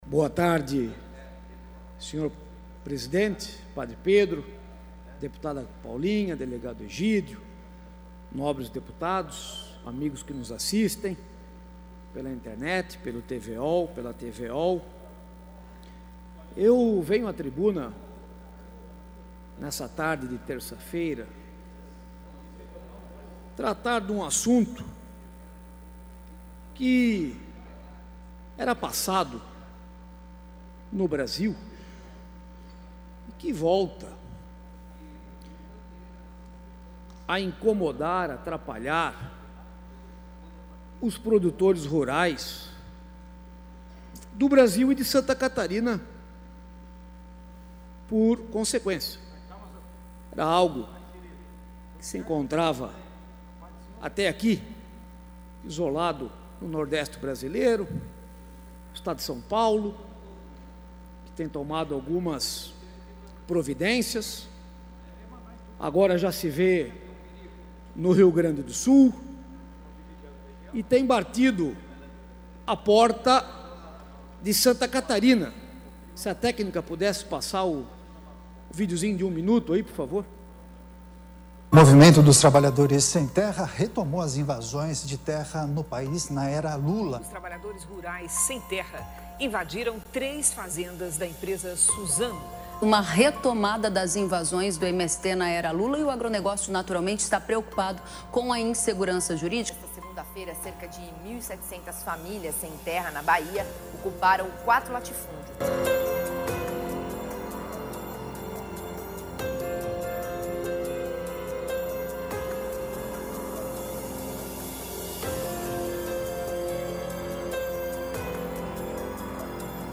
Pronunciamentos da sessão ordinária desta terça-feira (7)